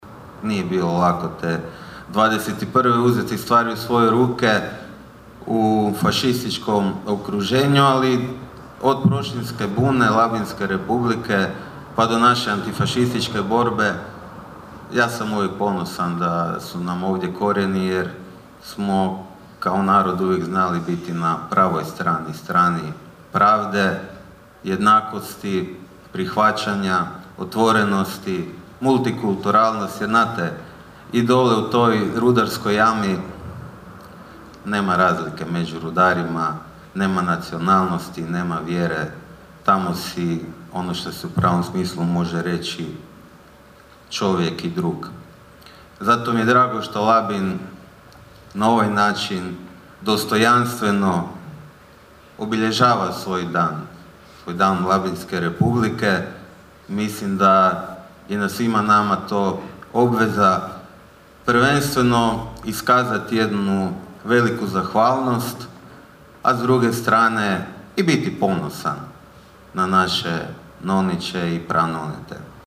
Svečanom akademijom „Iz srca dubine – u dubinu duše“ u Kinu Labin obilježena je 105. obljetnica Labinske republike.
ton – Boris Miletić).